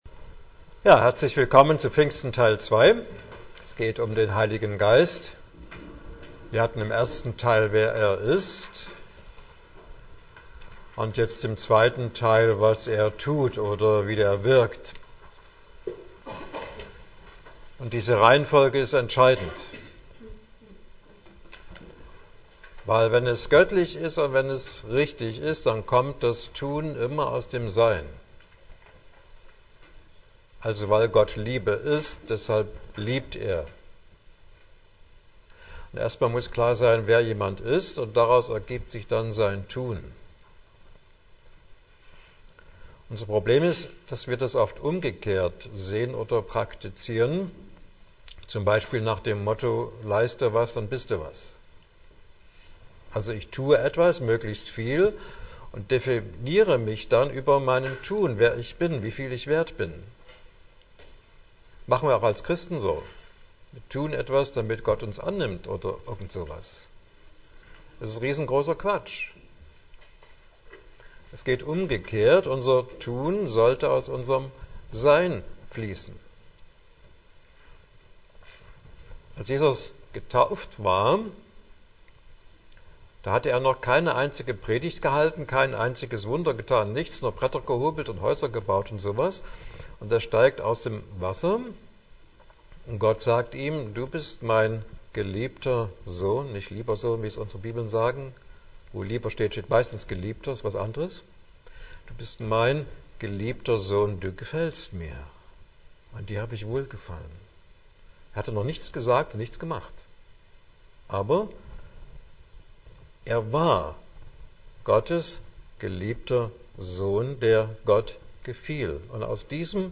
Teil 1 des 2. Vortrages – etwa 31 Minuten